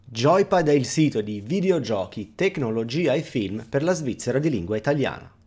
Per comparazione, abbiamo deciso di registare la stessa frase con altri 3 microfoni: quello integrato nelle Logitech Pro X, Epos B20 e il temutissimo Shure SM7B, quest’ultimo come golden standard e, come sempre, non un comparativo molto onesto.
C’è un leggero rimbombo in più con il microfono di Epos rispetto a quello di Blue, anche se sono stati impostati tutti e due in modalità cardioide.
BlueYetiXReview-EPOSB20.mp3